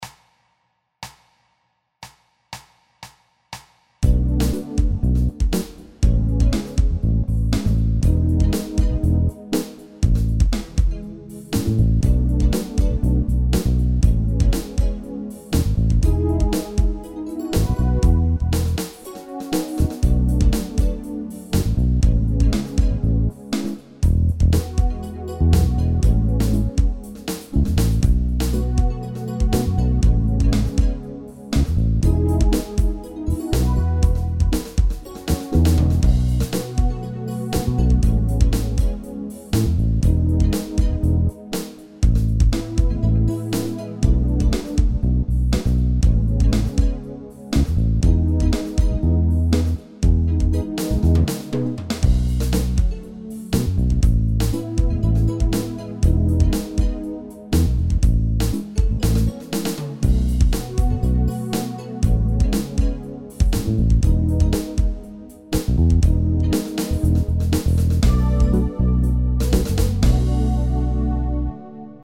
mp3 backing track